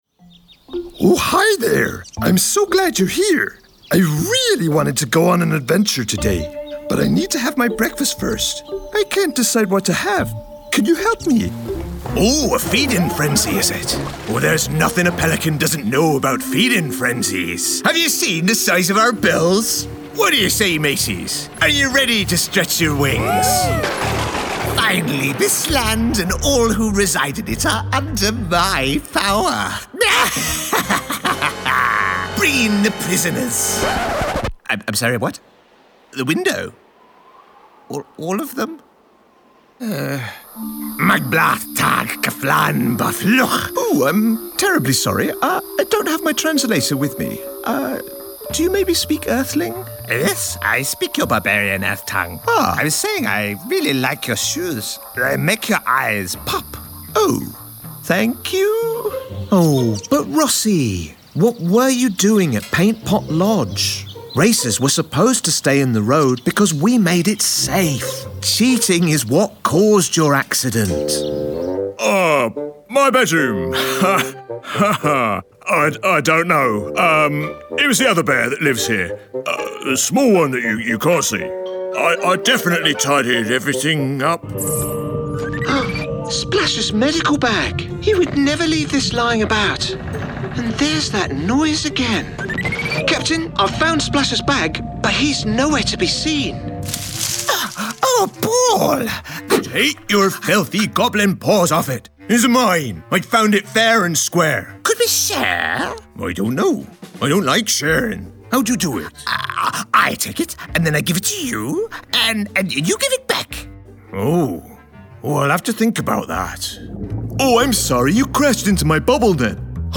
Male
Character / Cartoon
Multiple Kid Friendly Voices